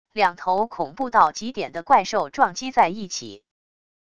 两头恐怖到极点的怪兽撞击在一起wav音频